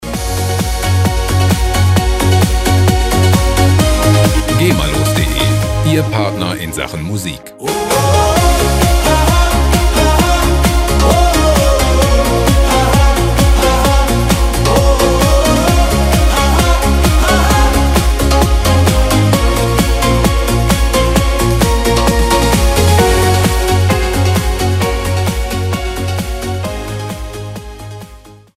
Gema-freie Schlager
Musikstil: Dance Schlager
Tempo: 131 bpm